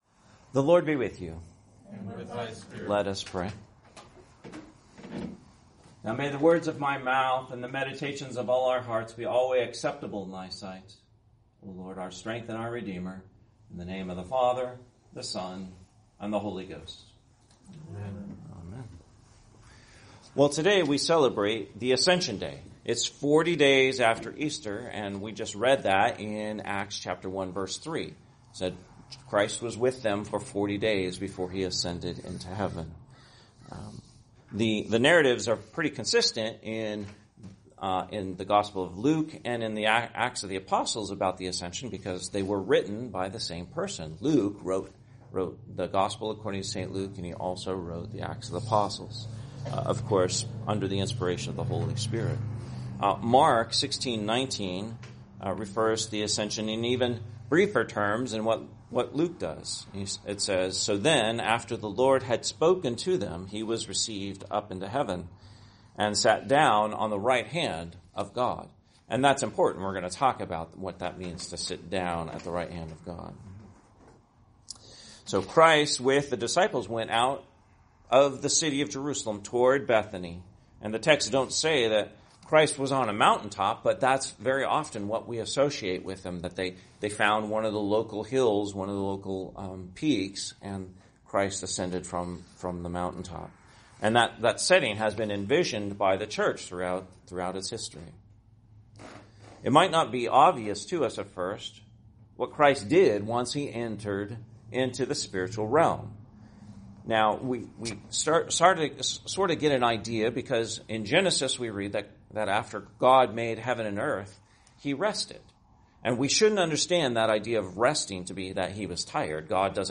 Sermon, Ascension Day, 2025